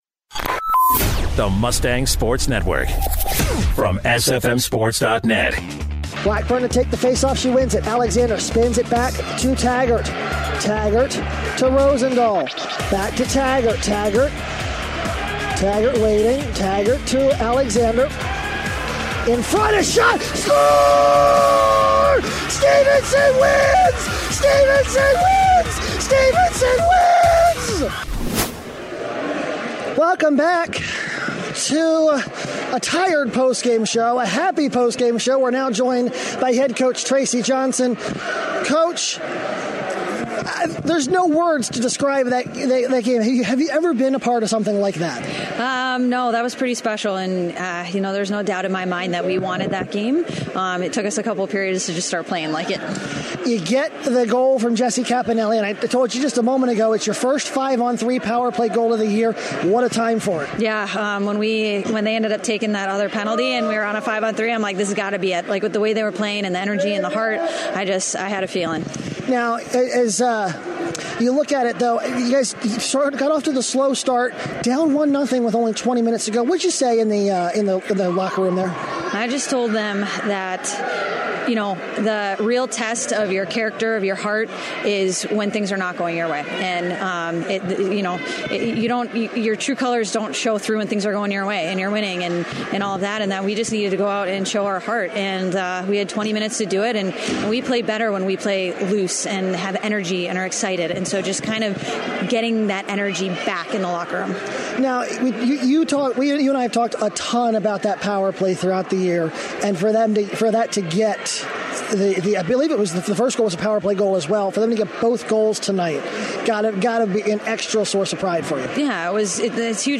Stevenson Women's Ice Hockey-CHC Championship Post Game Interview